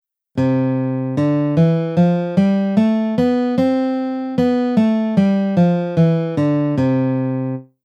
La gamme diatonique Majeur
La gamme de Do majeur est écrite comme suit